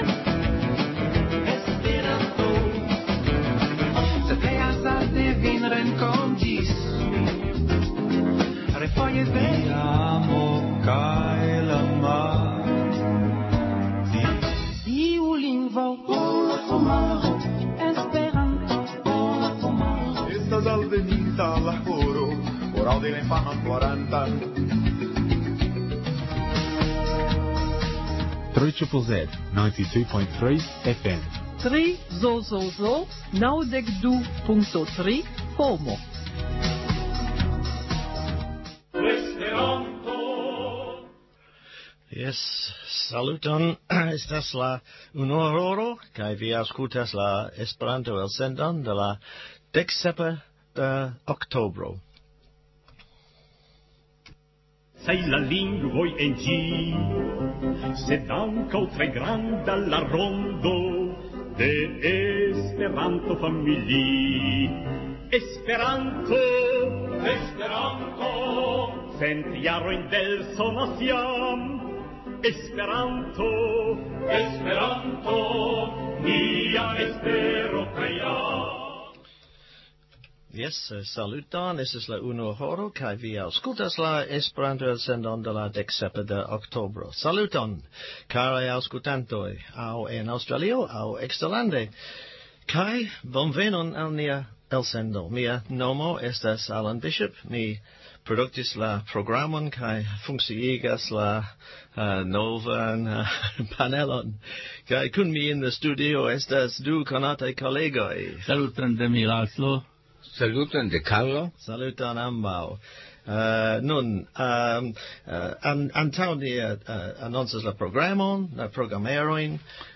Kanto : el Jomo friponas « Suno sunu !